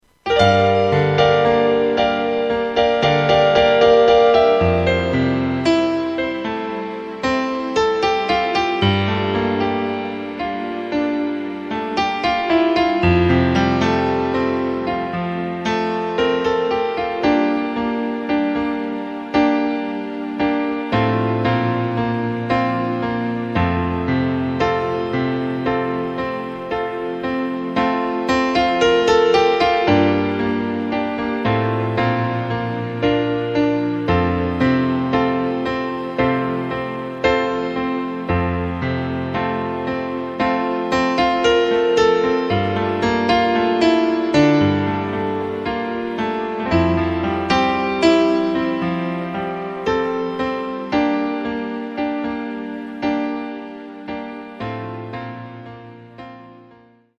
Takt:          4/4
Tempo:         114.00
Tonart:            Am
Playback mp3 mit Lyrics